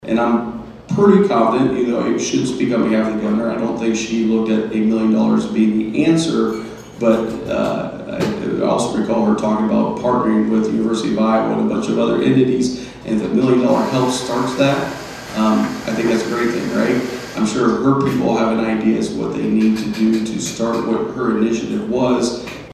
The Mahaska Chamber hosted its second Coffee and Conversation event of 2025 on Saturday morning at Smokey Row Coffee in Oskaloosa.
Senator Adrian Dickey said that the $1 million is more likely to be a starting point, rather than a complete answer to the problem.